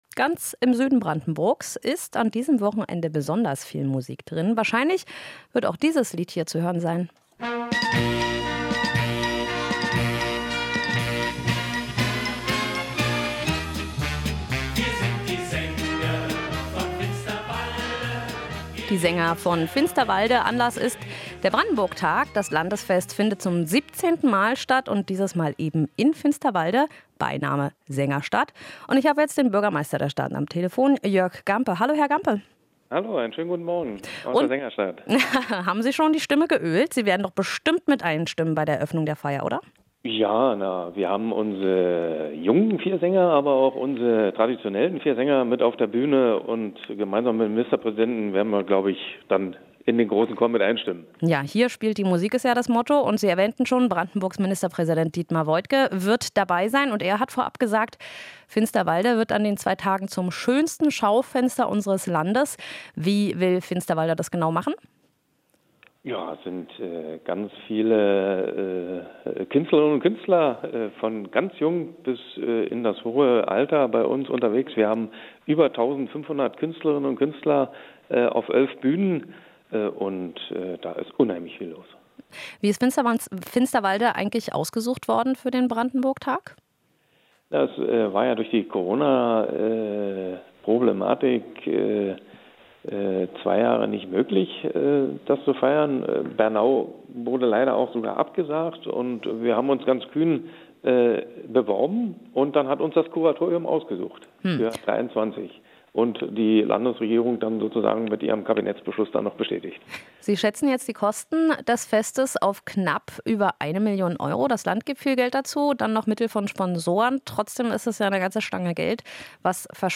Interview - "Schaufenster des Landes": Brandenburg-Tag in Finsterwalde